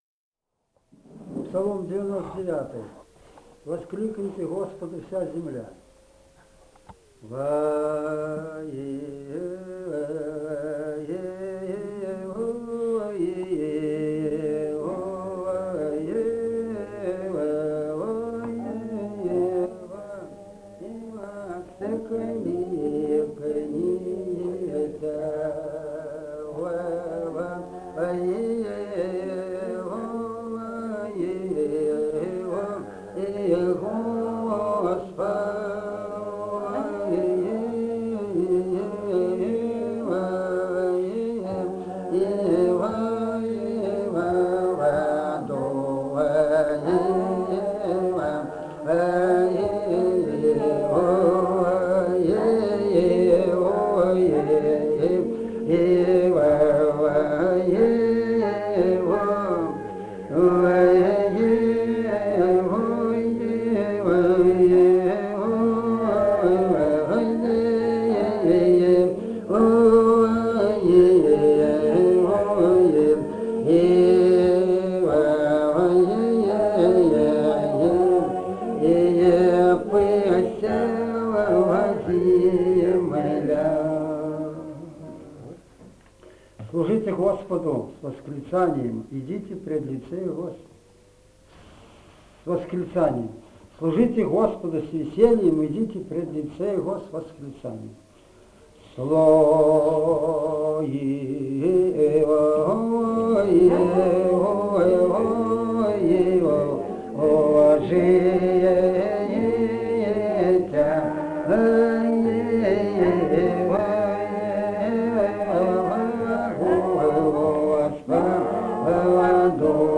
Этномузыкологические исследования и полевые материалы
Псалом 99 (брачный).
Грузия, г. Тбилиси, 1971 г.